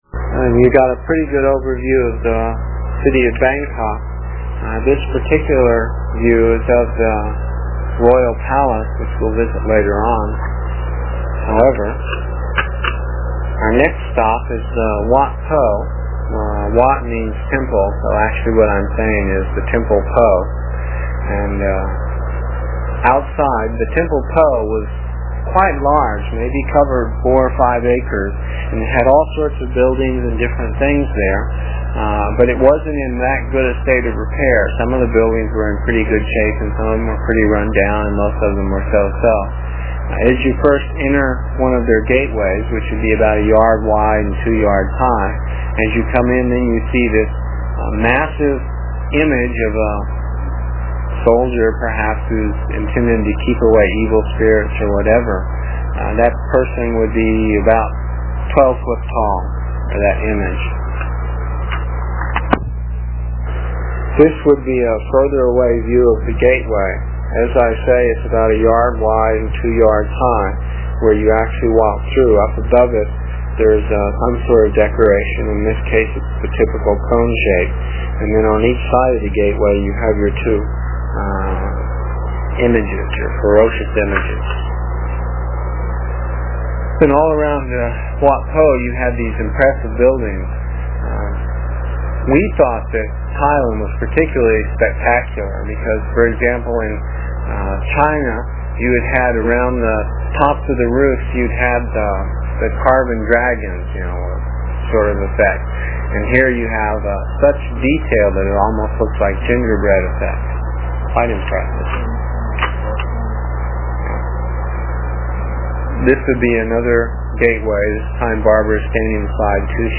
voice description of each slide that you can listen to while looking at the slides. It is from the cassette tapes we made almost thirty years ago. I was pretty long winded (no rehearsals or editting and tapes were cheap) and the section for this page is about four minutes and will take about a minute to download with a dial up connection.